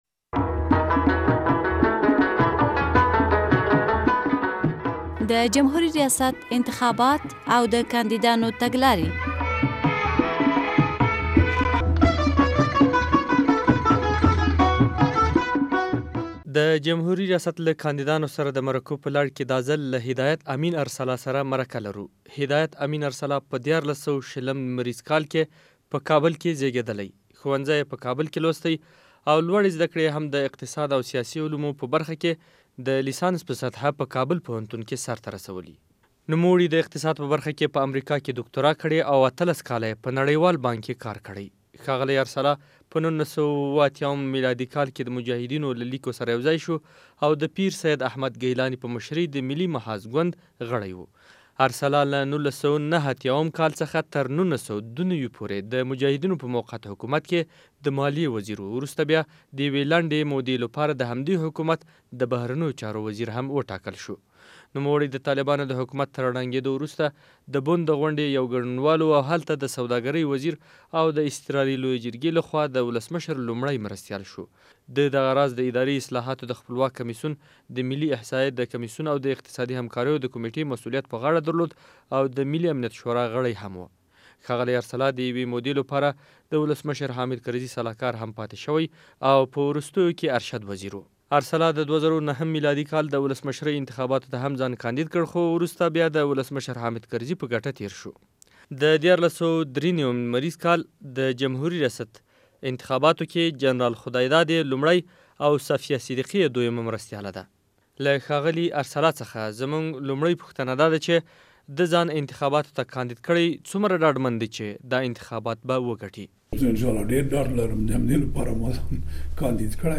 له هدایت امین ارسلا سره ځانګړې مرکه